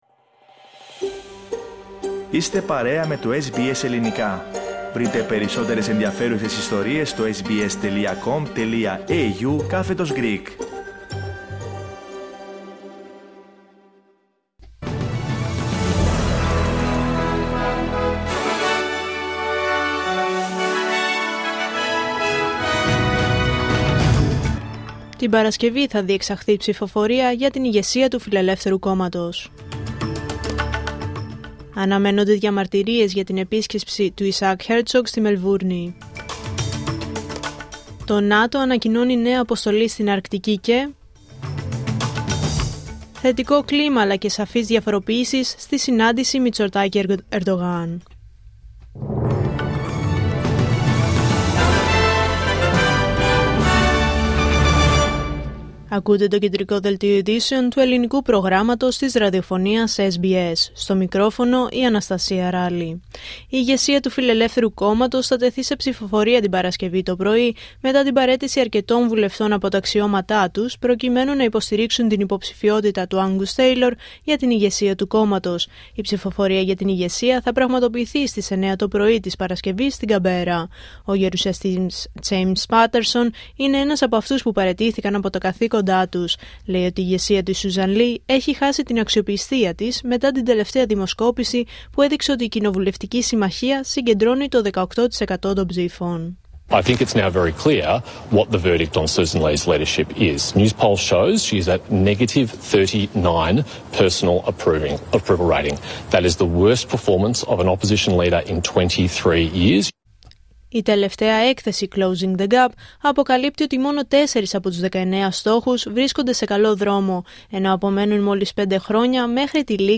Δελτίο Ειδήσεων Πέμπτη 12 Φεβρουαρίου 2026